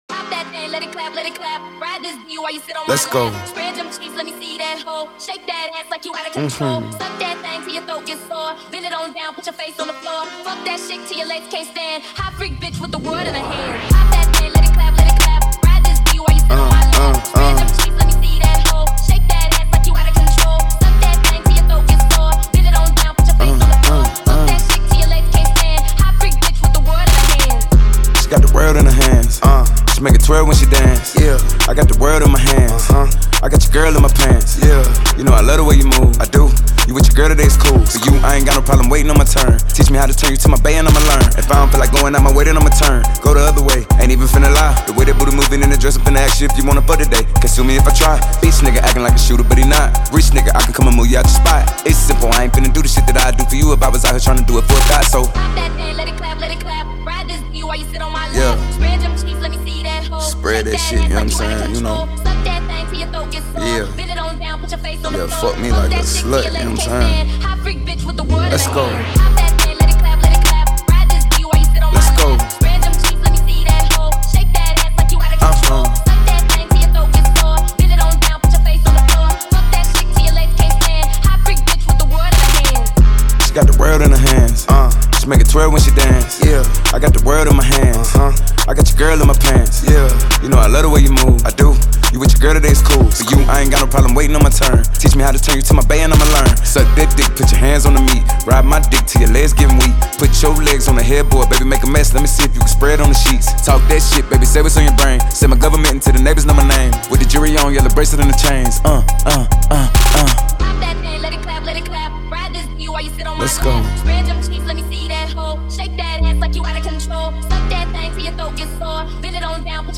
Трек размещён в разделе Рэп и хип-хоп / Зарубежная музыка.